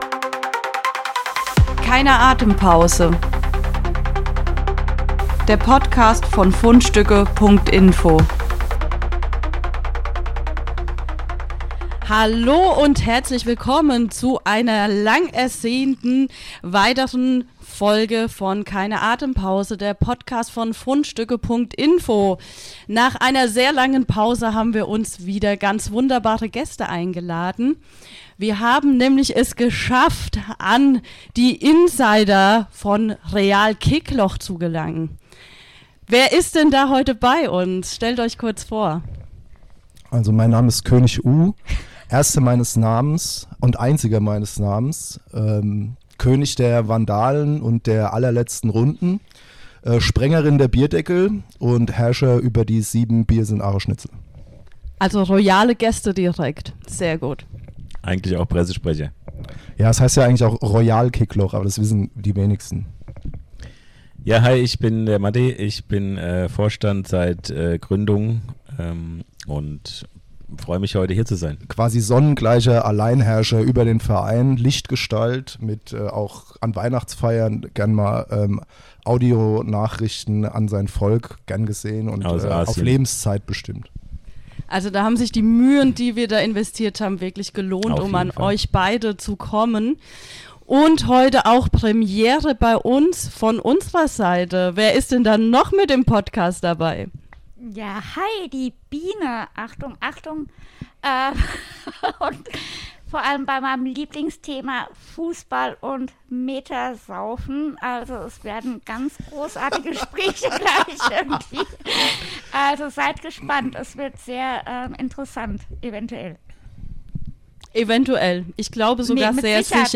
Wir sind stolz, wieder einmal herausragende Interviewpartner gefunden zu haben. Diesmal kommen sie vom Real Kickloch e.V. aus Aschaffenburg.